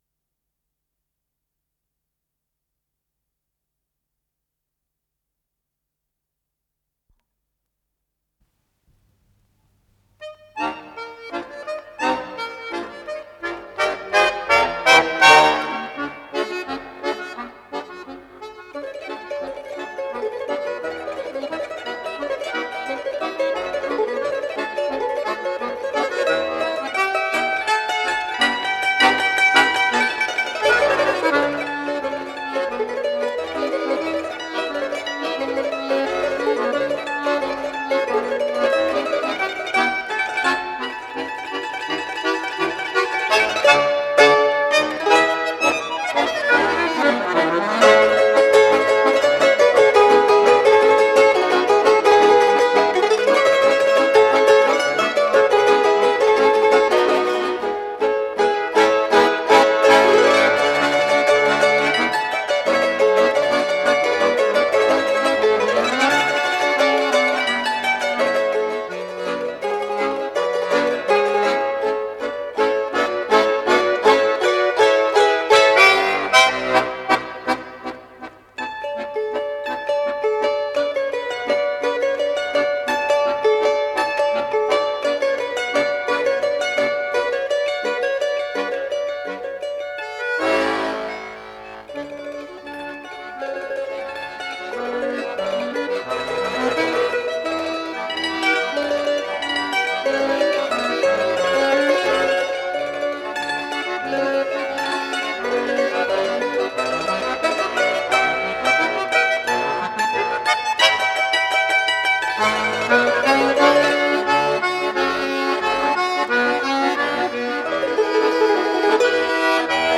звончатые гусли
баян